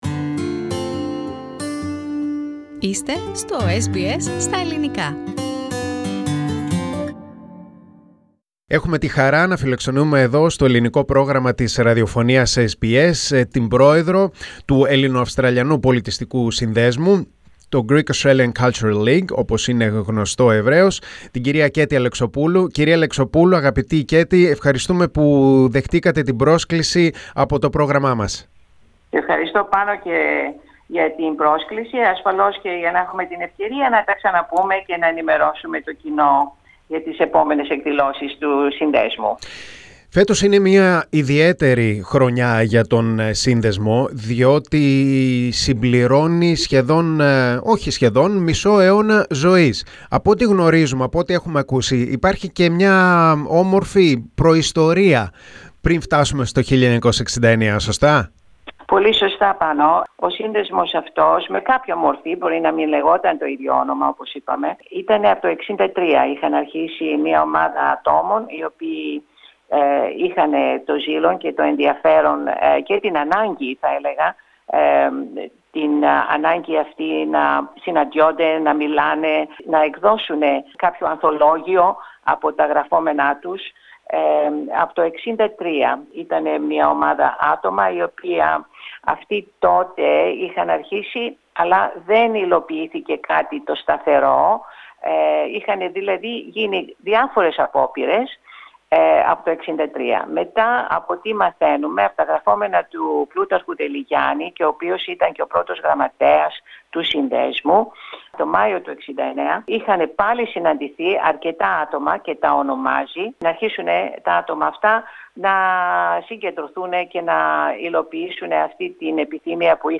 μιλά στο Ελληνικό Πρόγραμμα της Ραδιοφωνίας SBS.